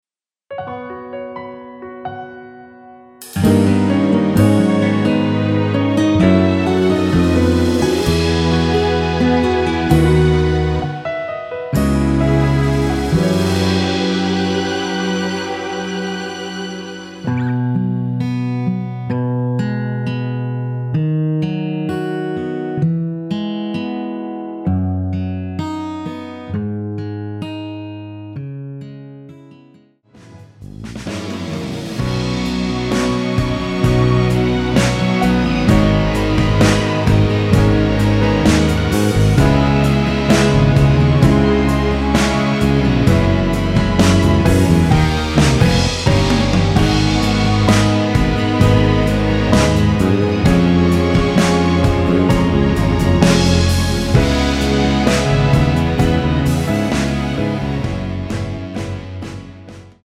앞부분30초, 뒷부분30초씩 편집해서 올려 드리고 있습니다.
중간에 음이 끈어지고 다시 나오는 이유는